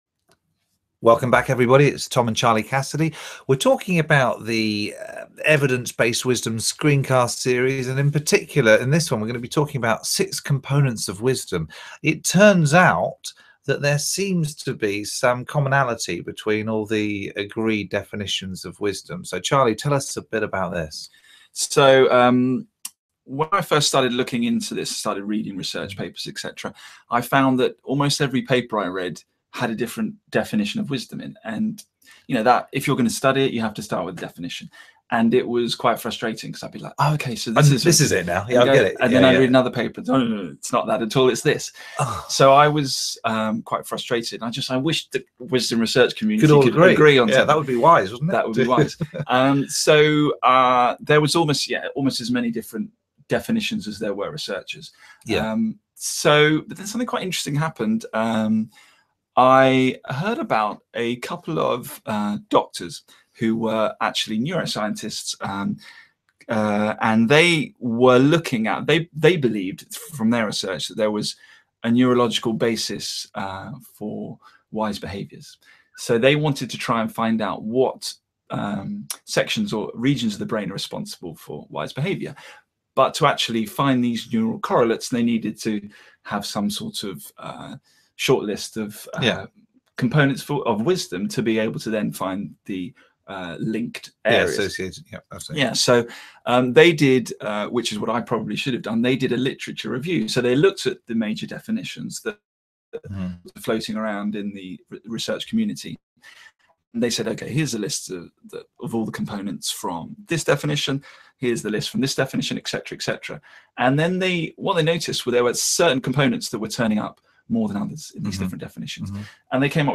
The Evidence-based Wisdom Screencast Series is a collection of short conversations discussing 10 of the major ideas and themes emerging from the field of Wisdom Research.